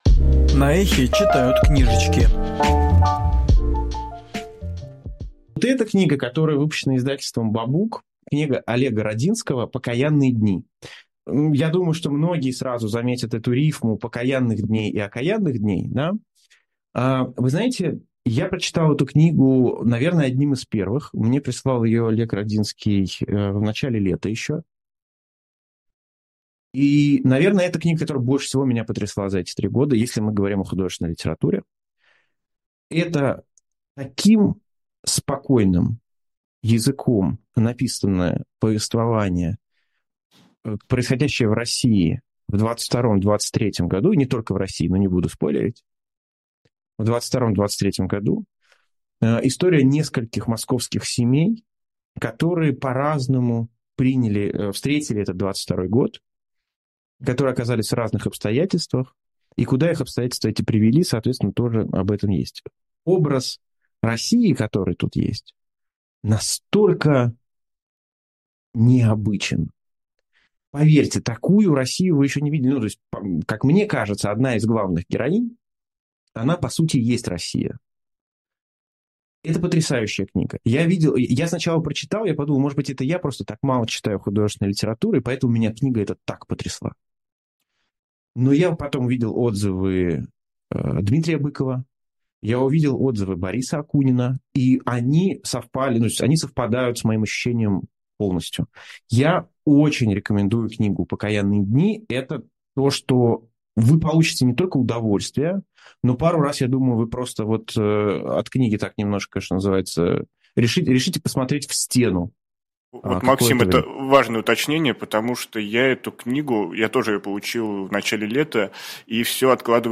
Фрагмент эфира «Книжное казино» от 4 ноября